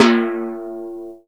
TIMP FAB-1.WAV